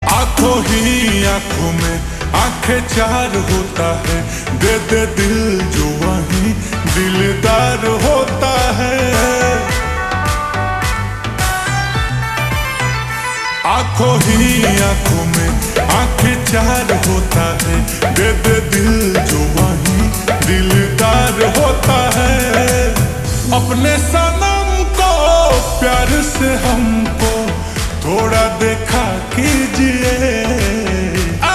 Bhojpuri Songs
(Slowed + Reverb)